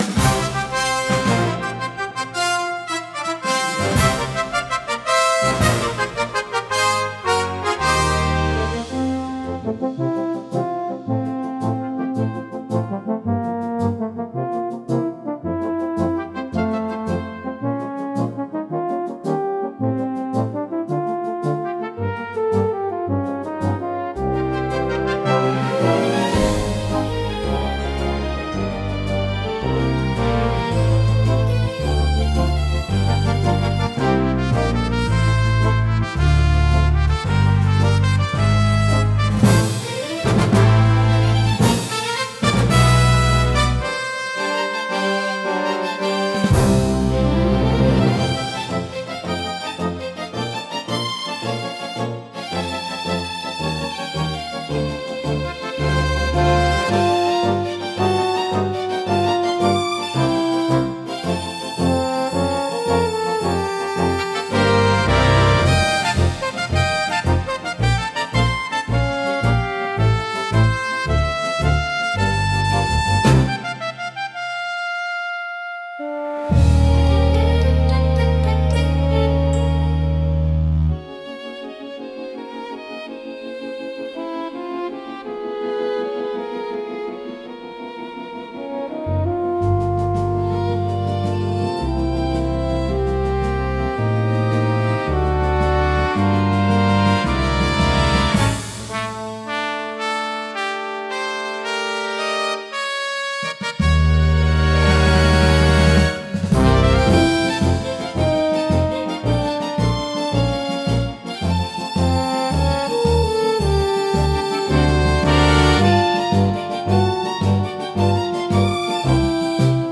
高揚感と喜びを強調し、場の雰囲気を盛り上げる役割を果たします。華やかで勢いのあるジャンルです。